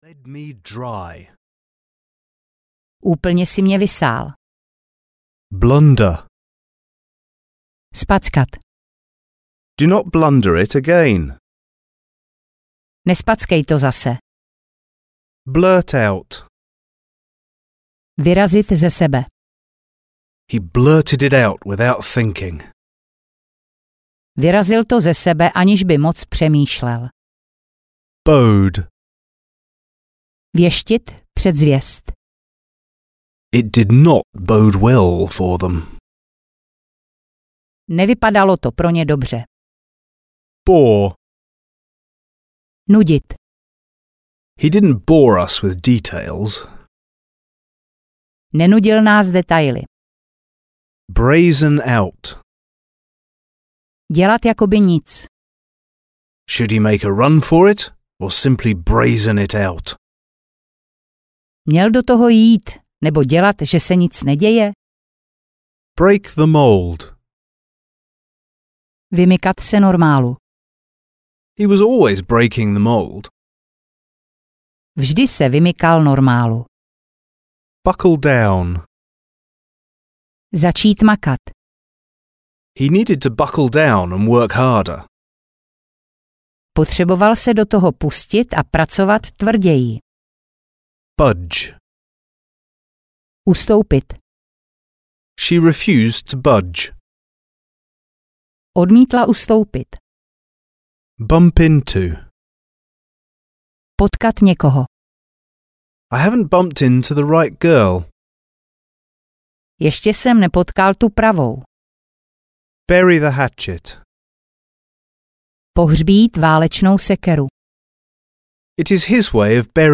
Audio knihaAngličtina - slovíčka pro pokročilé C1, C2
Ukázka z knihy